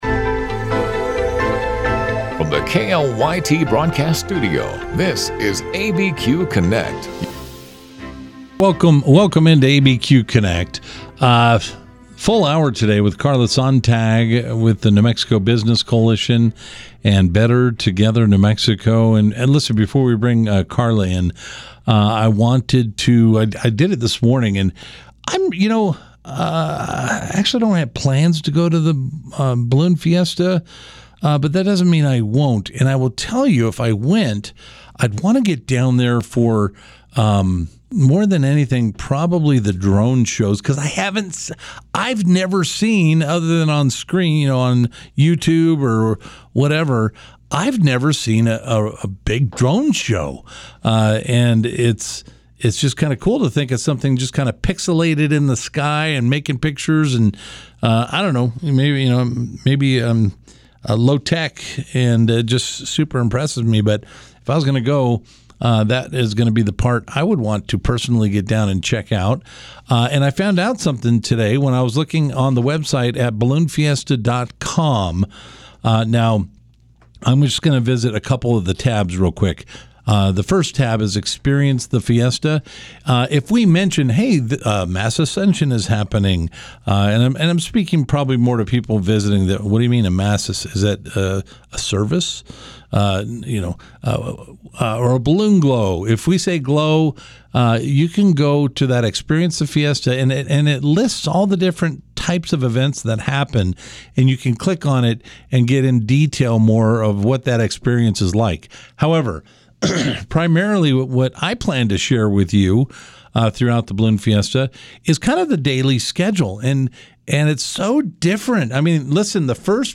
Albuquerque's live and local call-in show.
Supporting people with disabilities and their caregivers is the focus of this interview.